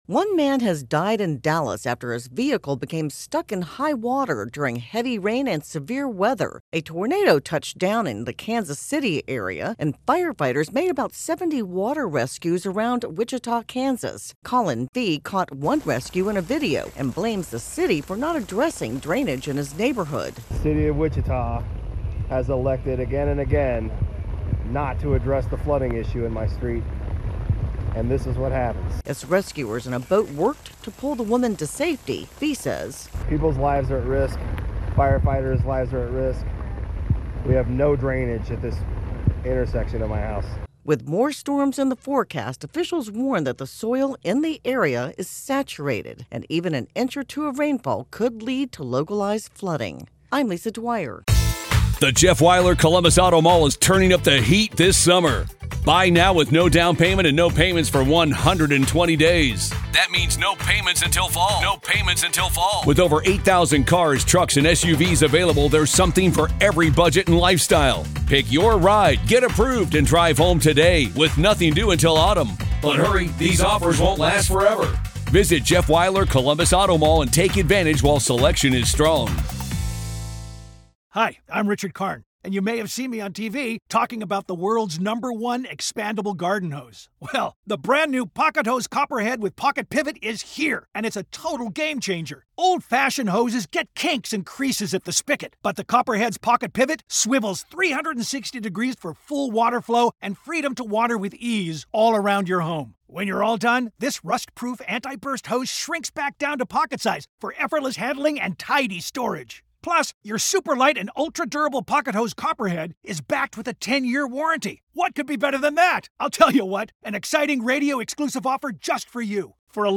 reports on deadly flooding and severe weather in the southern Midwest.